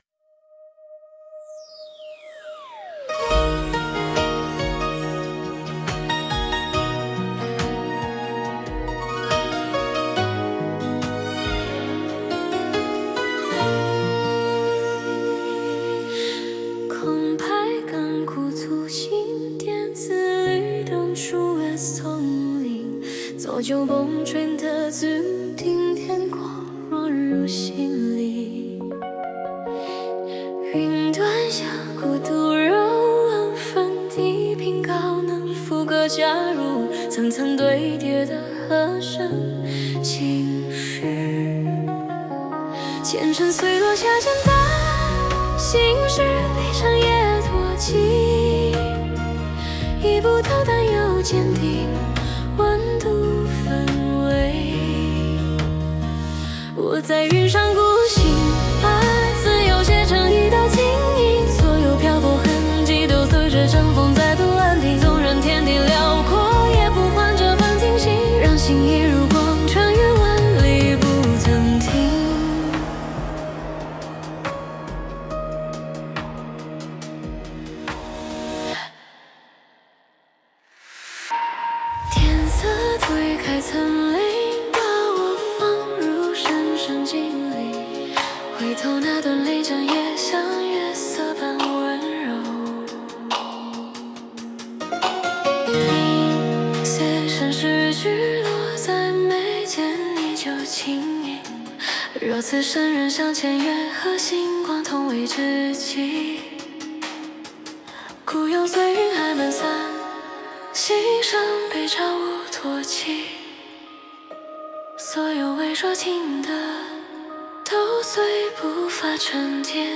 AI原创音乐
2025-11-20 分类：Dj古诗风 阅读(163) 评论(0)